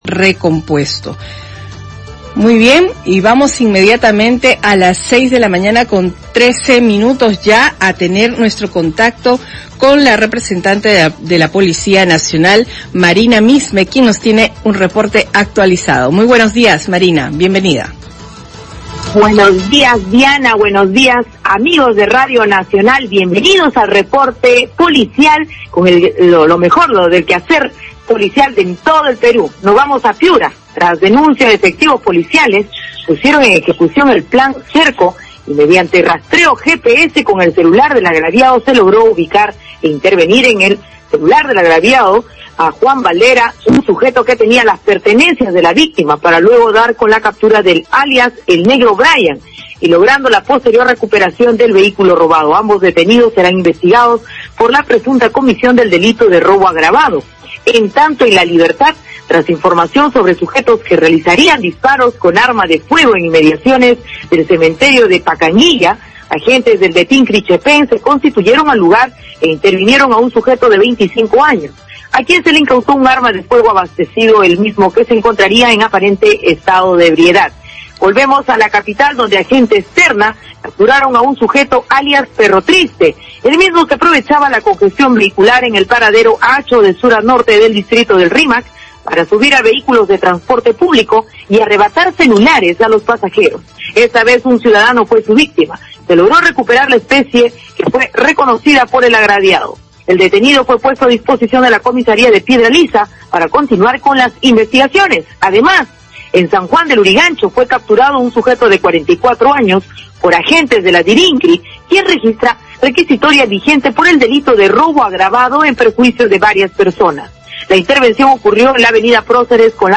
Reporte policial